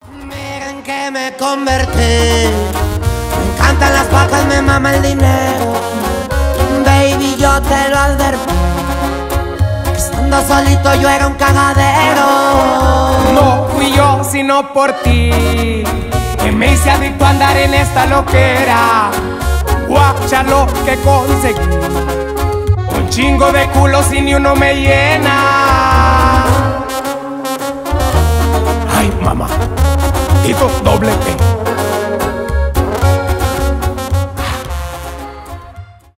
танцевальные , мексиканские
поп